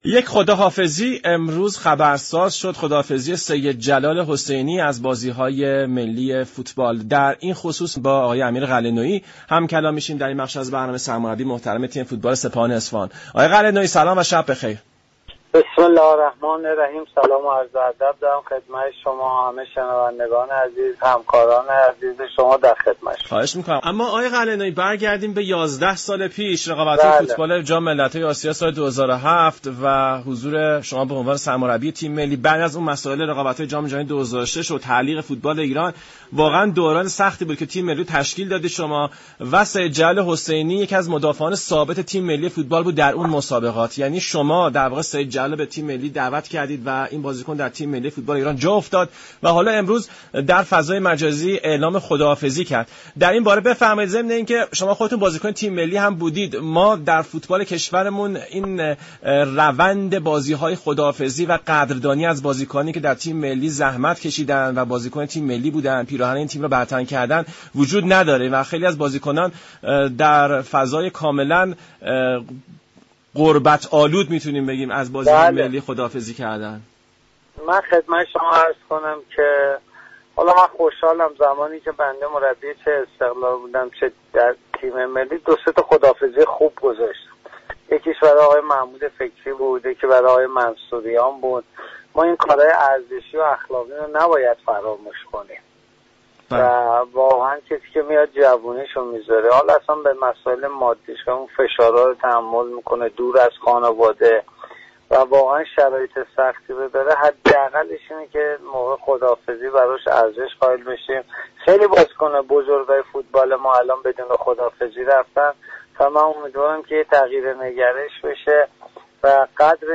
سرمربی تیم سپاهان در گفت و گو با رادیو ایران گفت: حاشیه ها و دغدغه های فوتبال ایران به قدری زیاد است كه حاشیه ها جای متن را گرفته است.